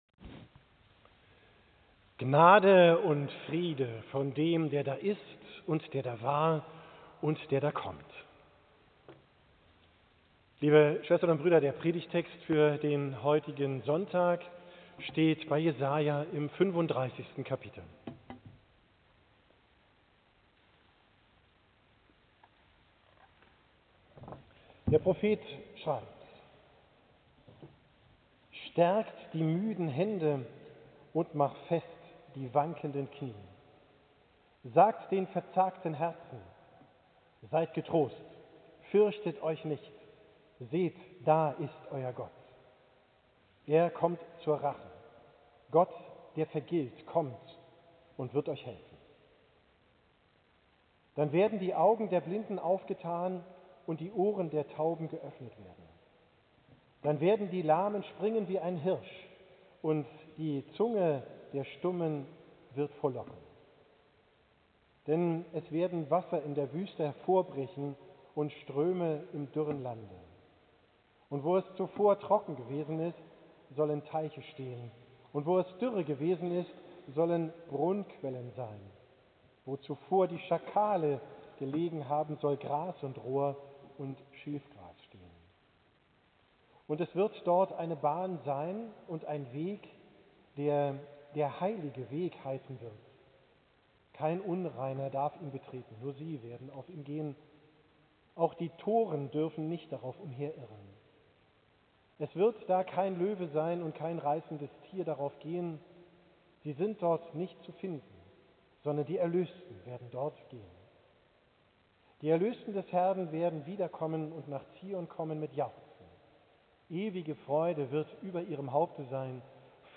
Predigt vom 2. Sonntag im Advent, 8.